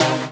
Synths
ED Synths 14.wav